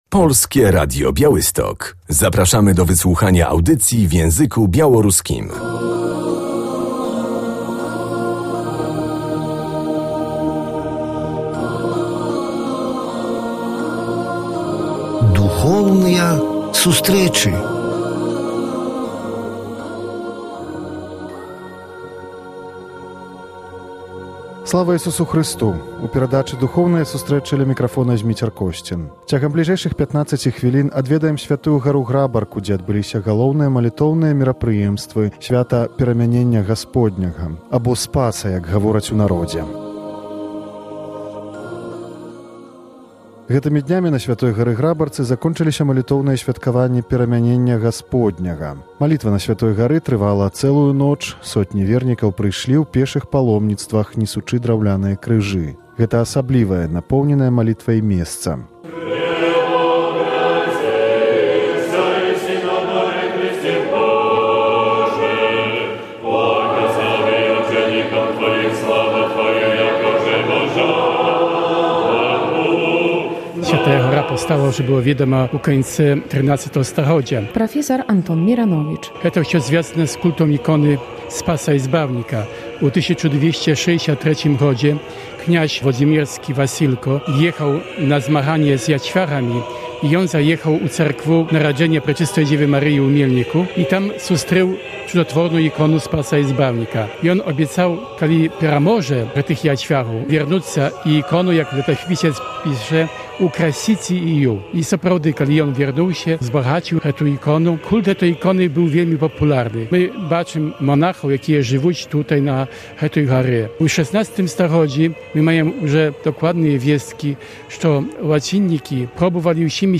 W audycji usłyszymy relację z modlitewnych uroczystości ku czci Przemienienia Pańskiego na św. Górze Grabarce.